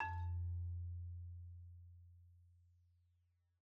Marimba_hit_Outrigger_F1_loud_01.wav